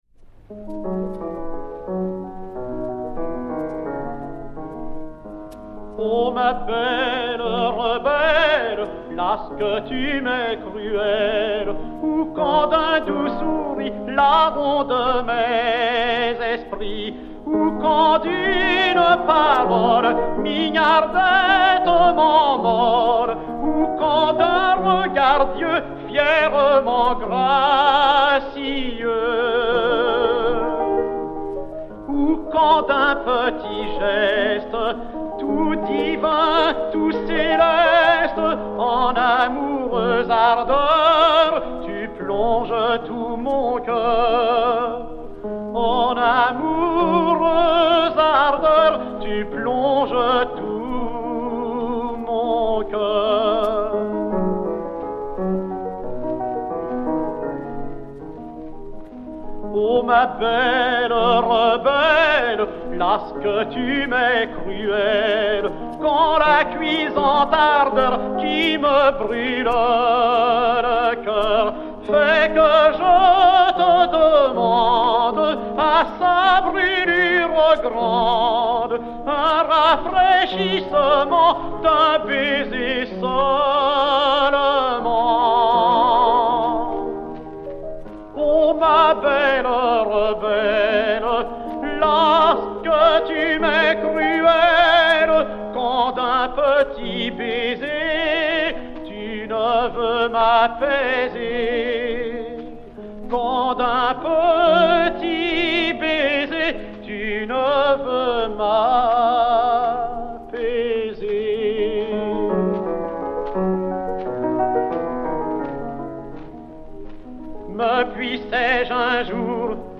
Mélodie, poésie de Jean Antoine de BAÏF (Venise, 19 février 1532 – Paris, 19 septembre 1589), musique de Charles GOUNOD (1850).
piano